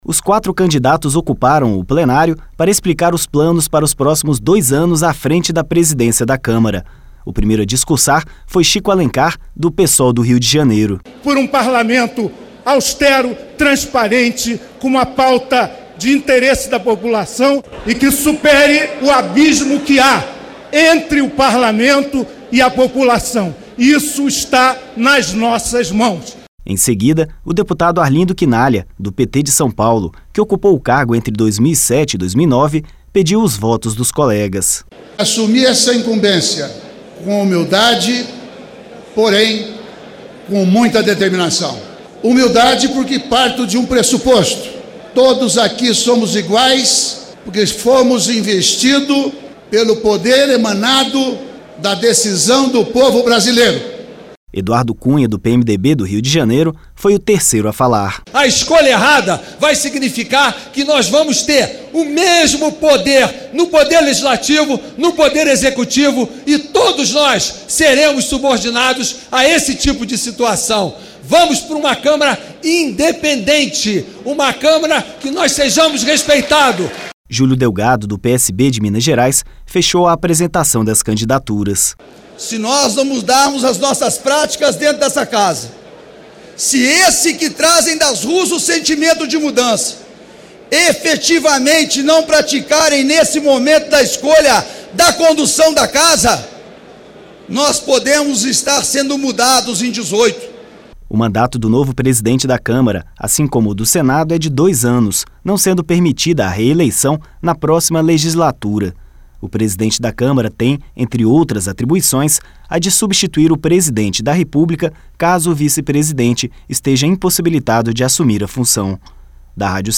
TÉC: Os quatro candidatos ocuparam o Plenário para explicar os planos para os próximos dois anos à frente da Presidência da Câmara.